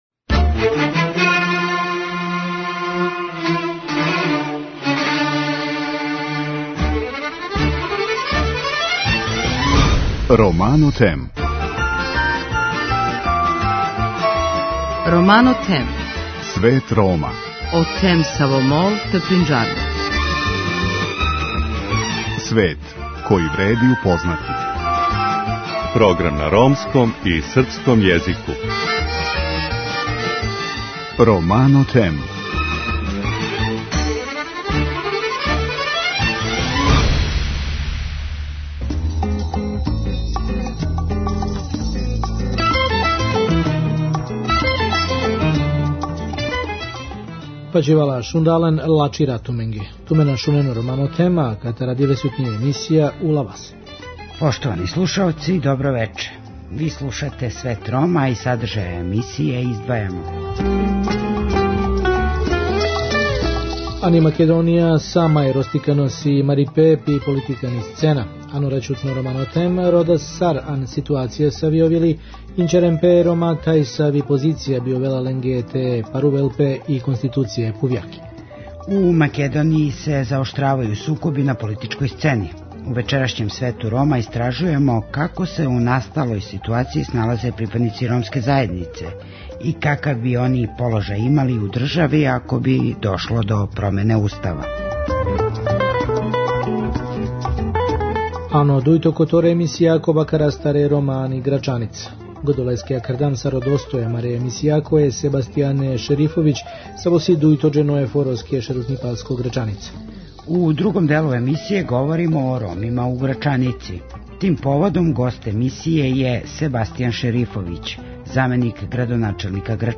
преузми : 7.20 MB Romano Them Autor: Ромска редакција Емисија свакодневно доноси најважније вести из земље и света на ромском и српском језику.
У другом делу емисије говоримо о Ромима у Грачаници. Тим поводом гост емисије је Себастијан Шерифовић заменик градоначелника Грачанице.